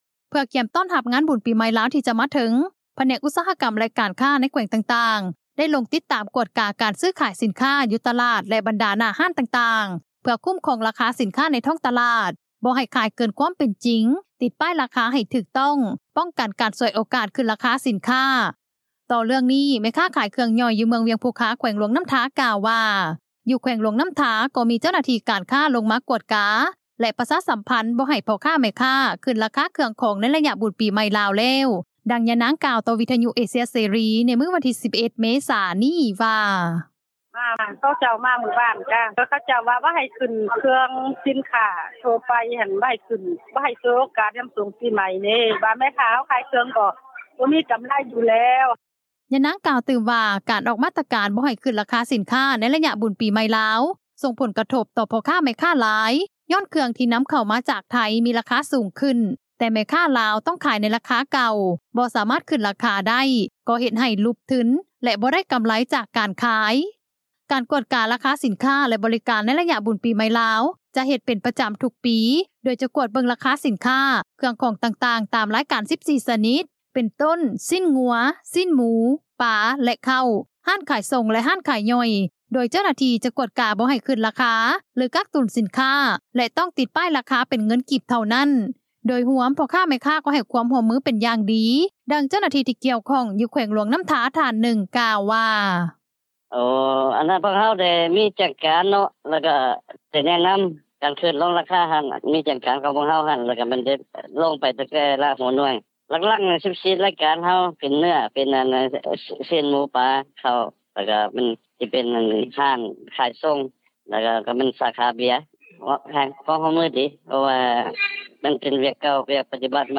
ດັ່ງເຈົ້າໜ້າທີ່ ທີ່ກ່ຽວຂ້ອງ ຢູ່ແຂວງຫຼວງນໍ້າທາ ທ່ານນຶ່ງ ກ່າວວ່າ:
ດັ່ງຊາວບ້ານ ຢູ່ເມືອງໂຂງ ແຂວງຈໍາປາສັກ ທ່ານນຶ່ງ ກ່າວວວ່າ:
ດັ່ງຊາວບ້ານ ຢູ່ແຂວງອັດຕະປື ນາງນຶ່ງ ກ່າວວ່າ: